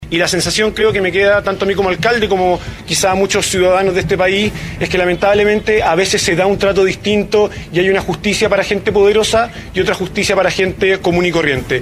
El alcalde Vodanovic en un punto de prensa ante los medios de comunicación manifestó » respecto a las medidas cautelares, no son las que nosotros habíamos solicitado, hay una suerte de decepción, porque pensábamos que el tribunal podía tomar una medida que demostrara a la ciudadanía que la corrupción es castigada de la misma manera independiente de donde venga